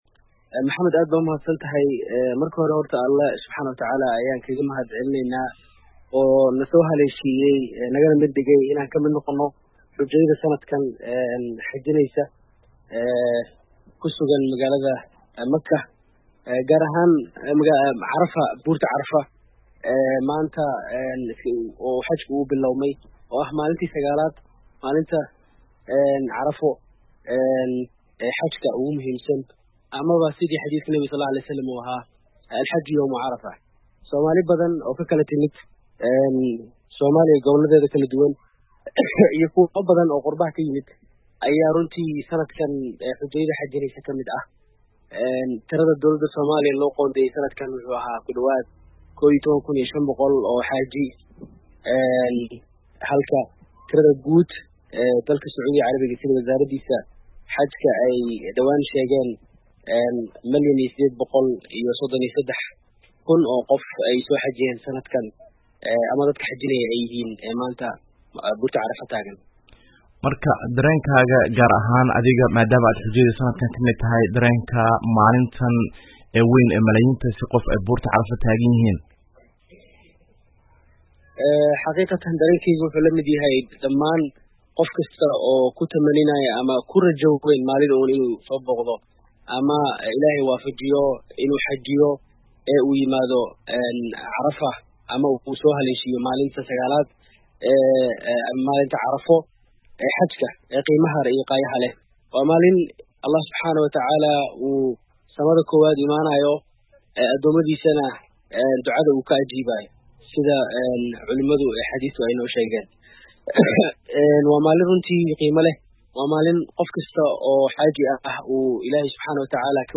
Wareysi ku saabsan Xujeyda maanta taagnaa Banka Carafo